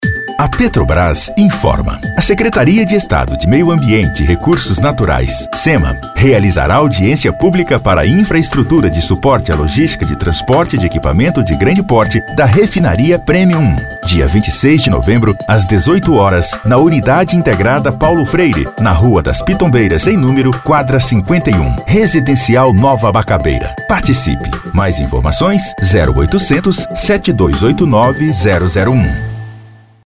DMVoz Produções - Locução profissional